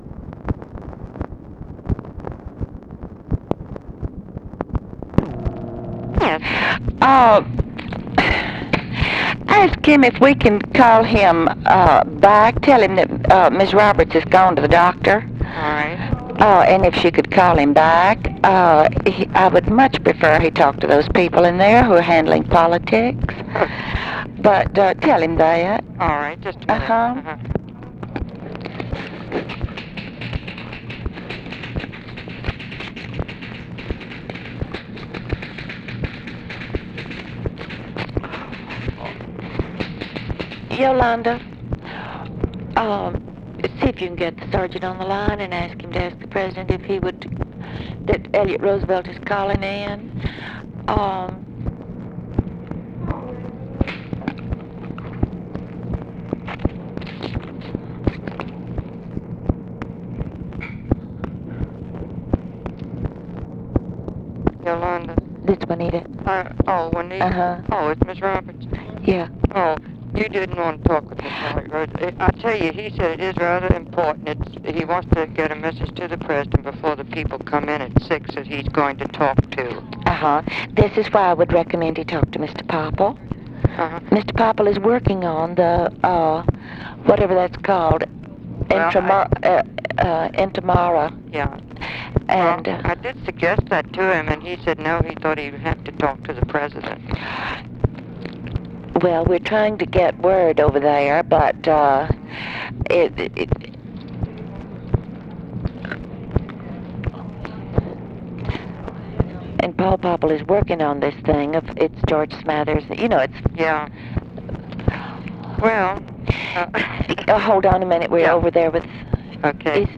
Conversation with TELEPHONE OPERATOR and OFFICE CONVERSATION
Secret White House Tapes